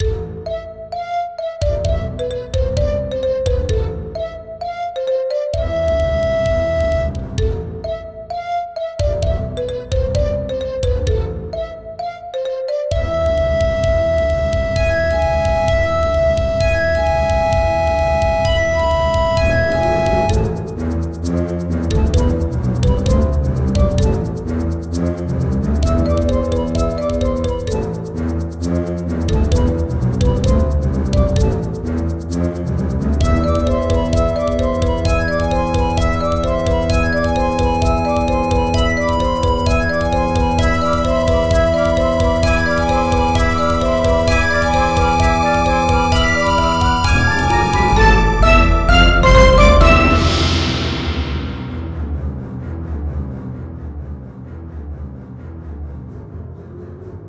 パンフルートとマリンバで始まる緊張のメロディーがコントラバスに引き継がれ、一時の静寂を得る。
ループ